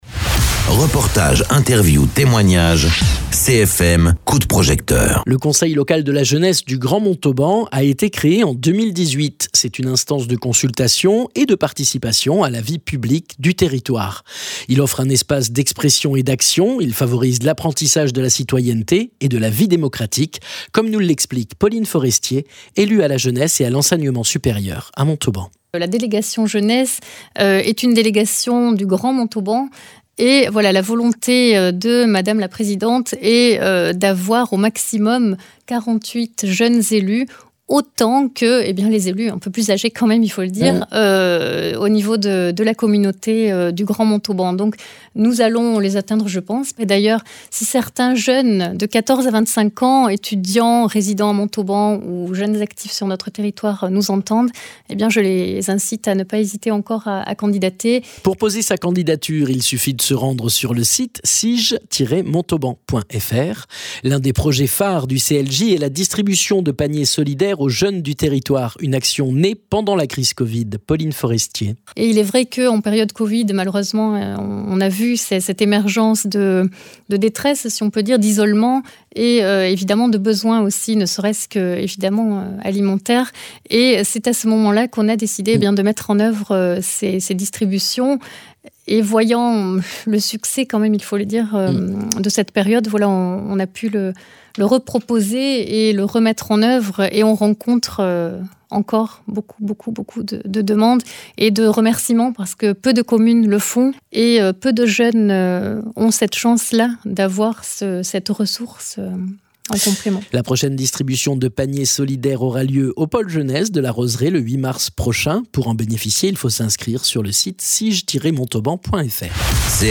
Interviews
Il favorise l’apprentissage de la citoyenneté et de la vie démocratique comme nous l’explique Pauline Forestier élue à la jeunesse et à l’enseignement supérieur à Montauban.